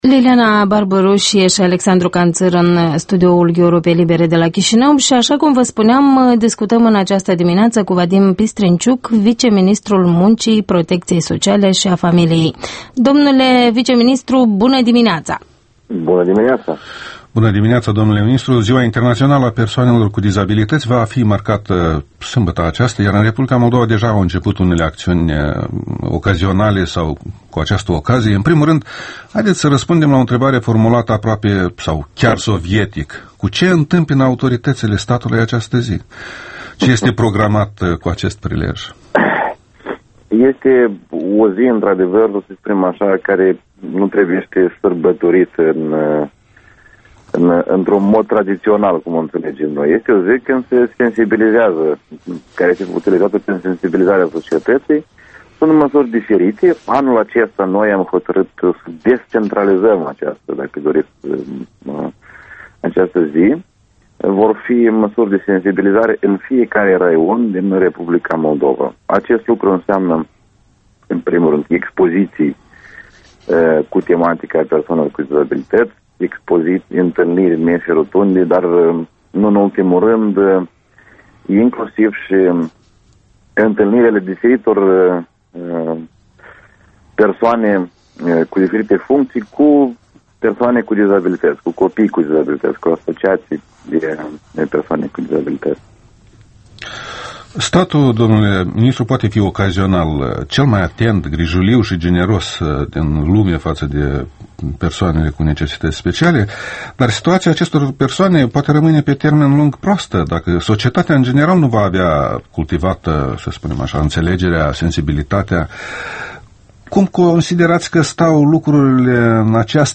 Un interviu cu ministrul adjunct al Muncii, Protecţiei Sociale şi Familiei, Vadim Pistrinciuc.